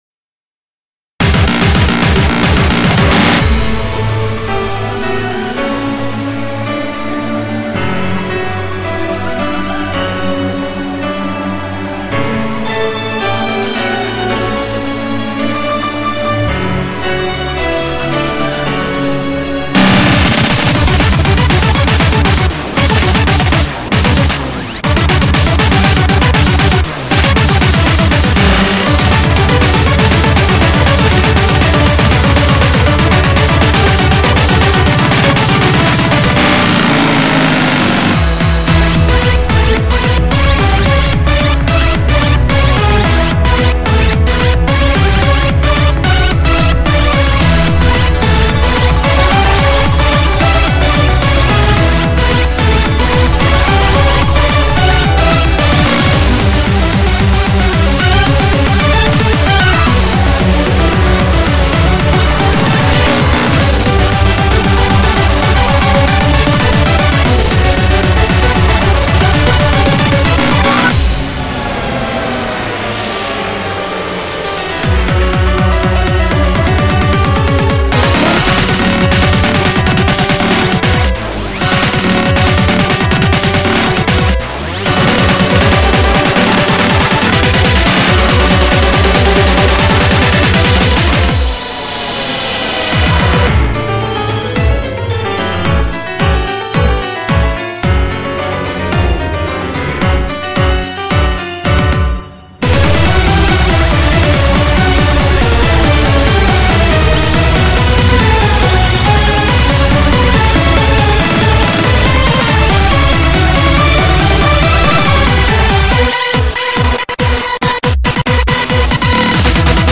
BPM55-280
Audio QualityPerfect (High Quality)
Comments[Progressive IDM]